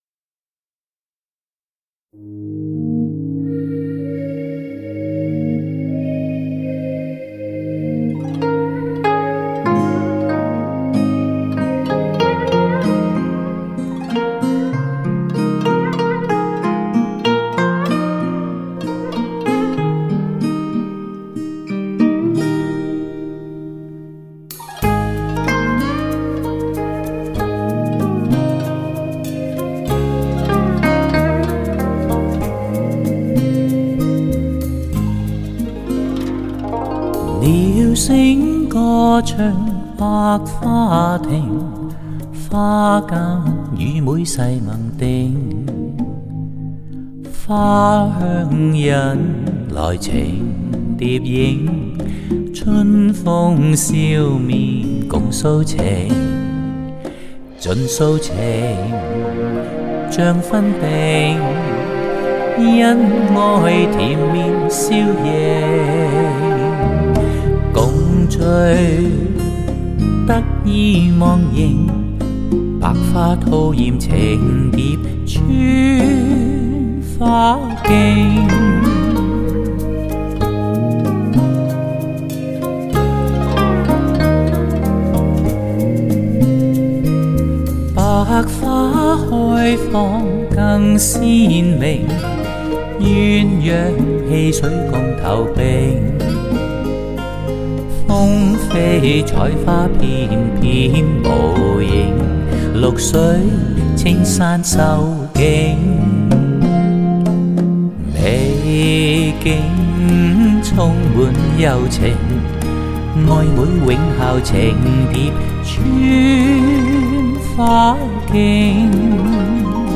音色更接近模拟(Analogue)声效
强劲动态音效中横溢出细致韵味
粤听越有味道，粤语经典 十二首选 升华版 殿堂人声 民歌味道
发烧界男声首席代表